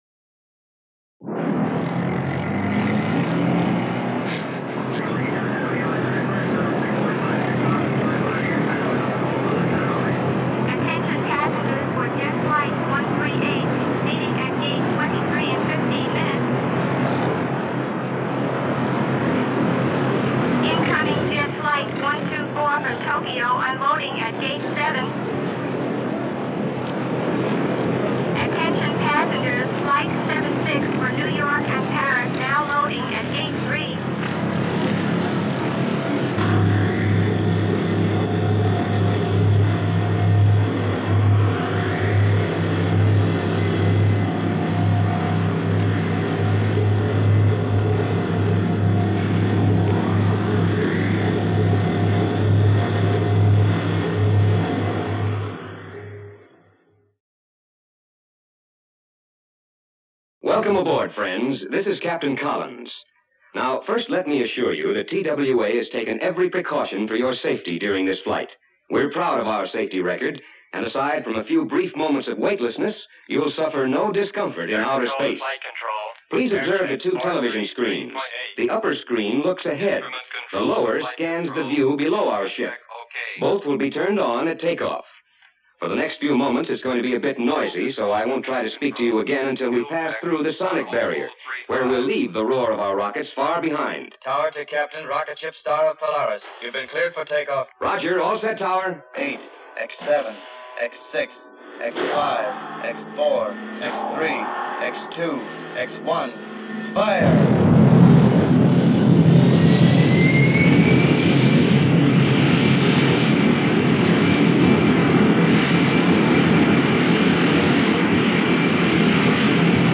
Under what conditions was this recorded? Original attraction audio…